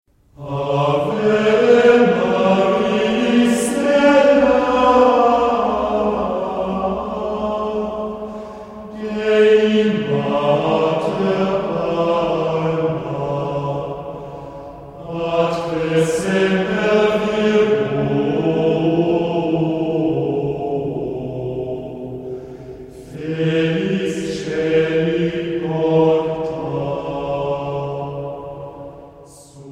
für vier vierstimmige Chöre (1861)